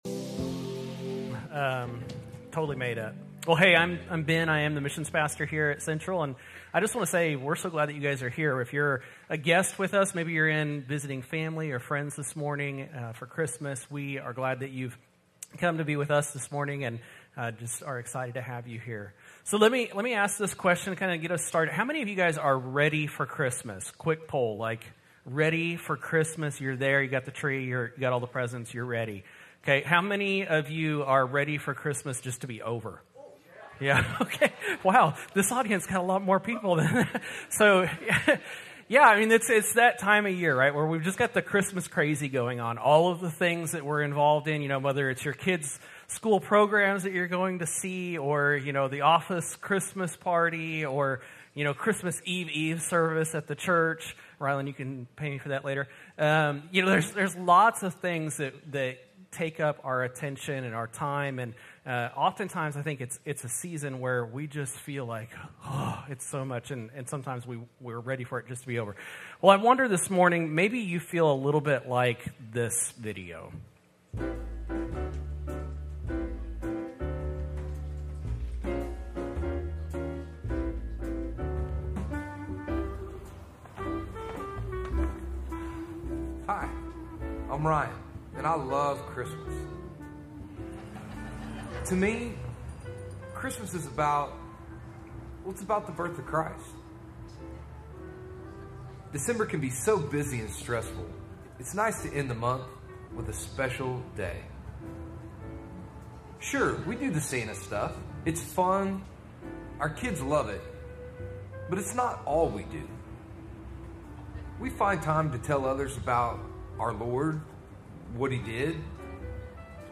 A message from the series "Sermons."